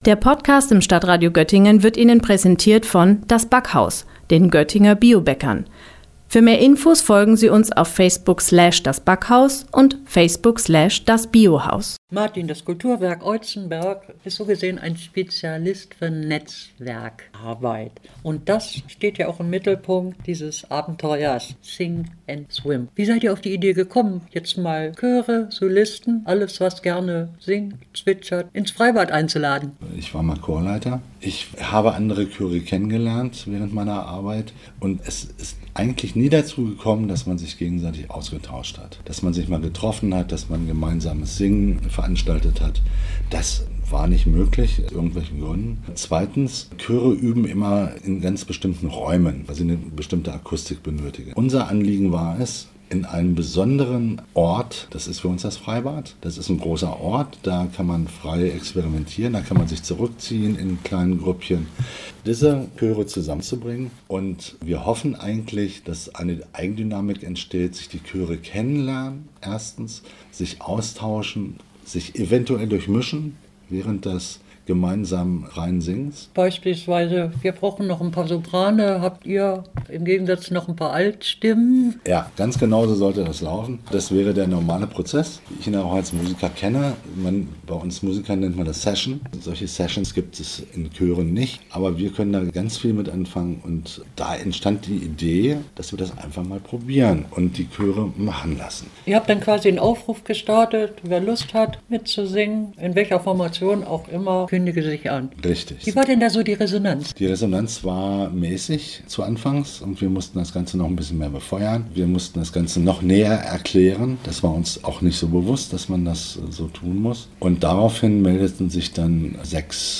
Beiträge > Sing & Swim zum Festivalstart „Kultur im Kreis“ - StadtRadio Göttingen